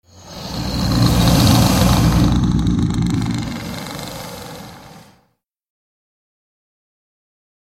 Звук рычания злой мумии которая ожила